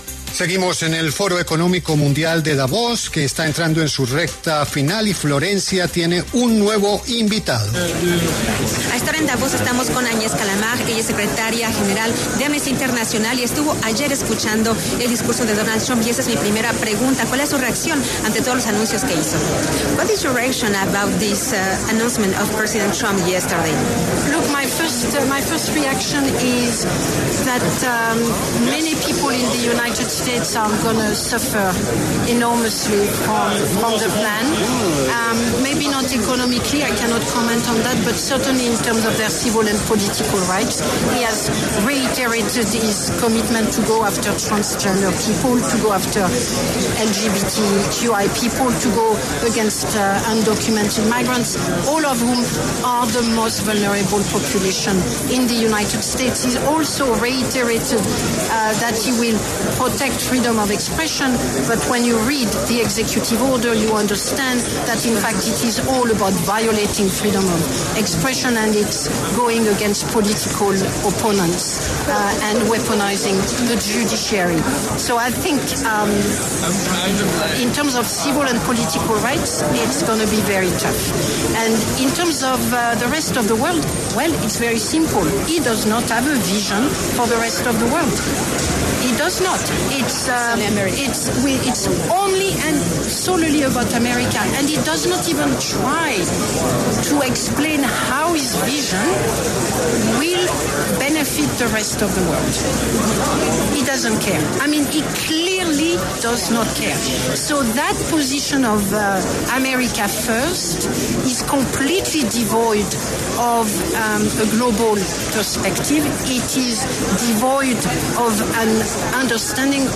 Desde el Foro de Davos, Agnés Callamard, secretaria general de Amnistía Internacional, conversó con La W sobre las declaraciones del nuevo presidente de Estados Unidos, Donald Trump.